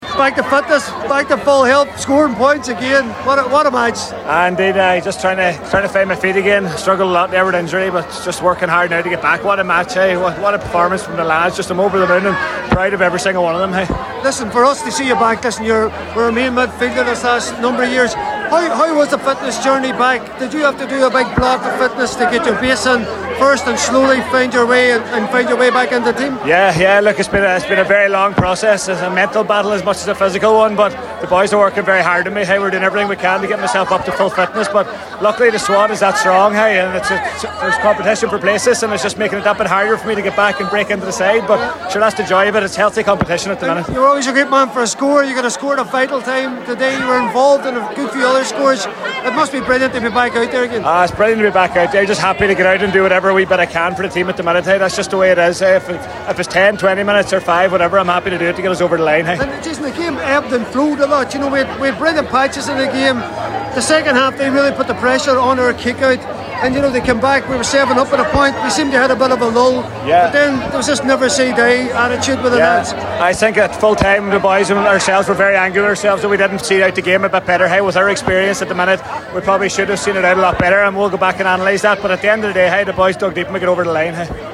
caught up with a number of the Donegal players after an absorbing Ulster SFC decider in Clones